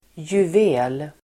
Ladda ner uttalet
juvel substantiv, jewel Uttal: [juv'e:l] Böjningar: juvelen, juveler Synonymer: ädelsten Definition: slipad ädelsten Idiom: skön juvel ("skojare, bedragare") Sammansättningar: juvel|handlare (jeweller)